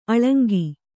♪ aḷaŋgi